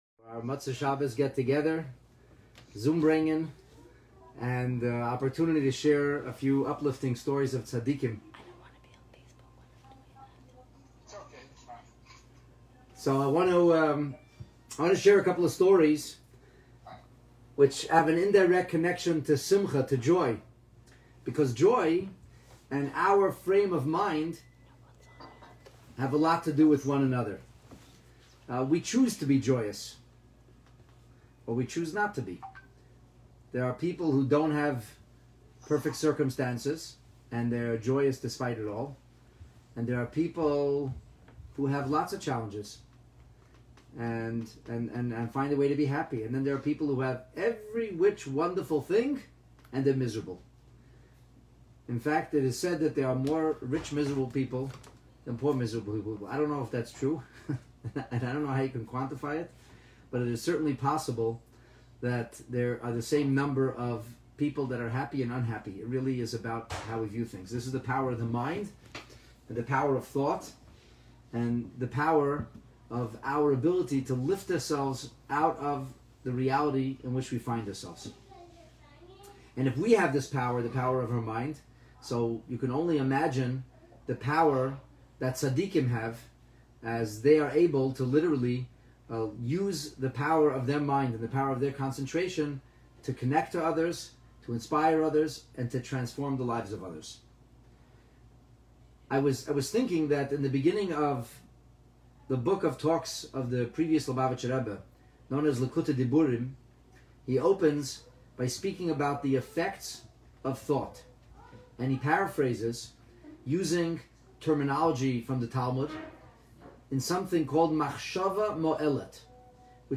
Motzei Shabbat Mishpatim Zoombrengen - Telepathic Connection Between Rebbes And Chassidim